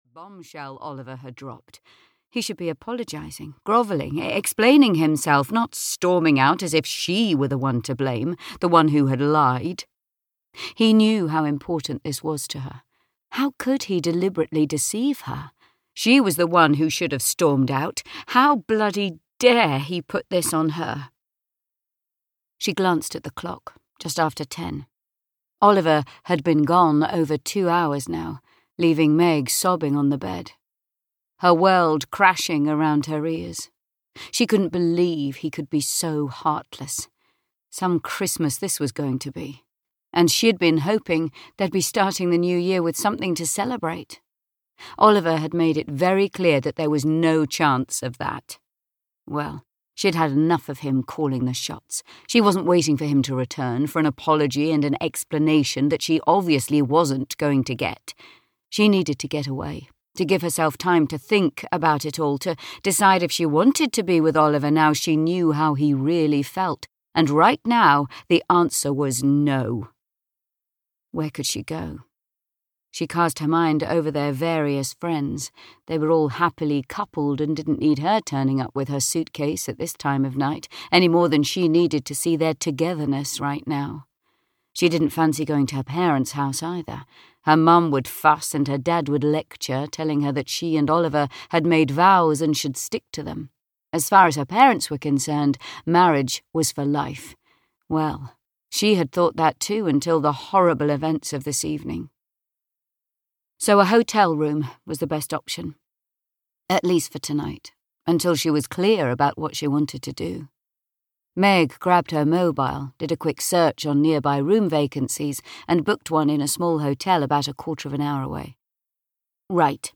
Single All the Way (EN) audiokniha
Ukázka z knihy